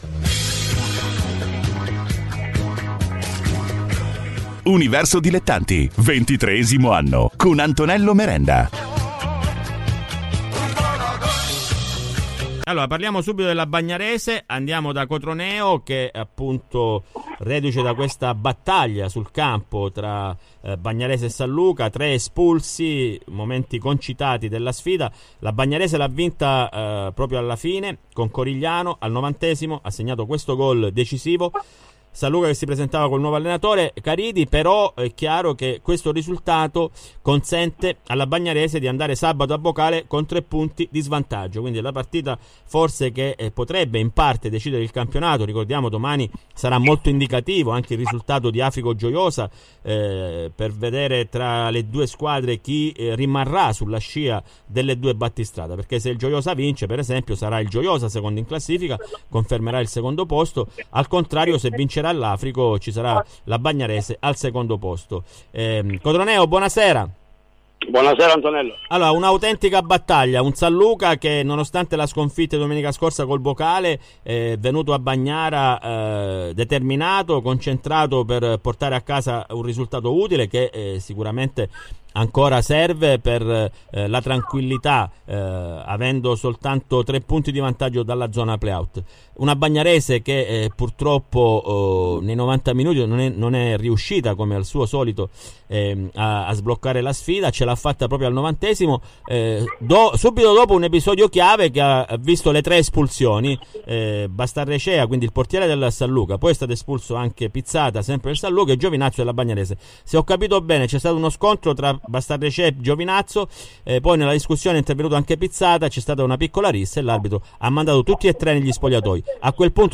Le interviste ai protagonisti di Universo Dilettanti